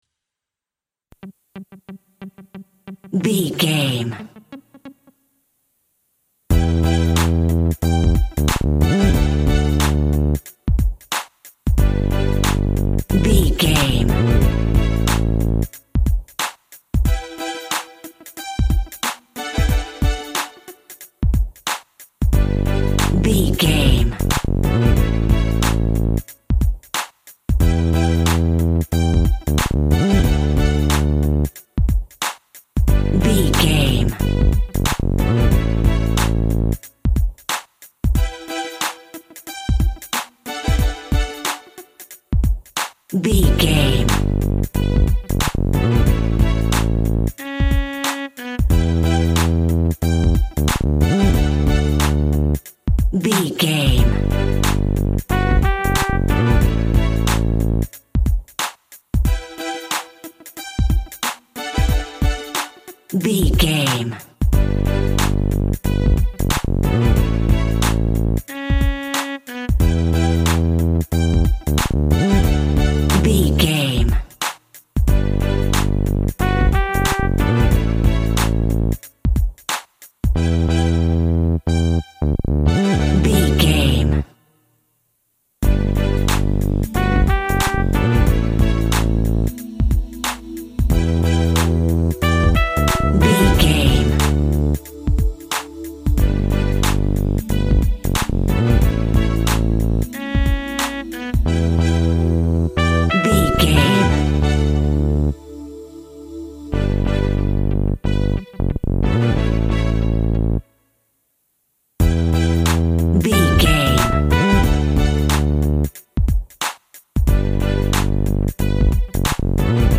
Hip Hop Epic Music.
Ionian/Major
synth lead
synth bass
hip hop synths